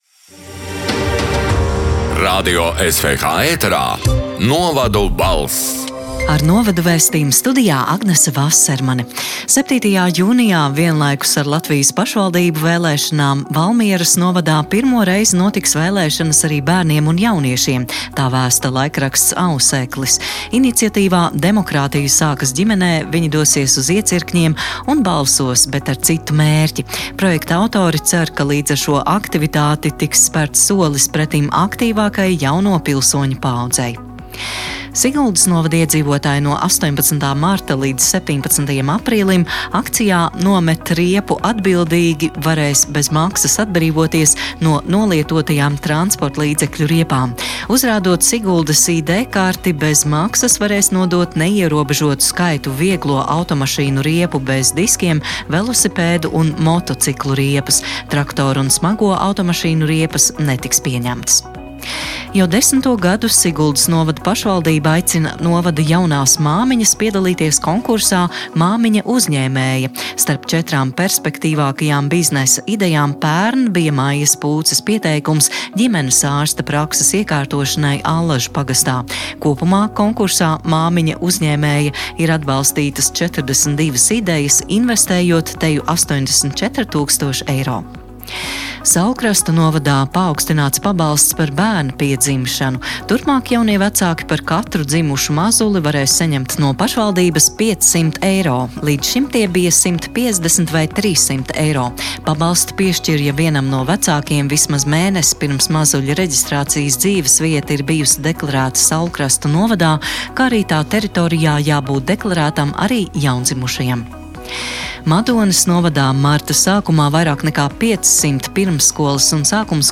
“Novadu balss” 12. marta ziņu raidījuma ieraksts: